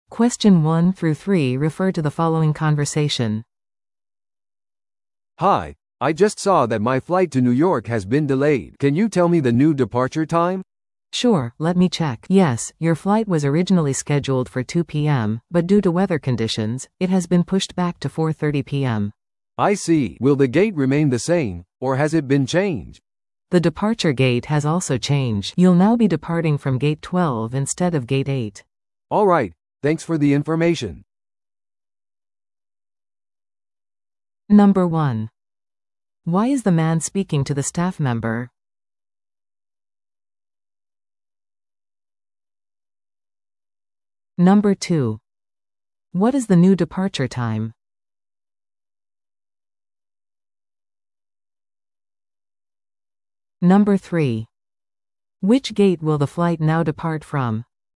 PART3は二人以上の英語会話が流れ、それを聞き取り問題用紙に書かれている設問に回答する形式のリスニング問題です。
No.1. Why is the man speaking to the staff member?